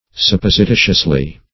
[1913 Webster] -- Sup*pos`i*ti"tious*ly, adv.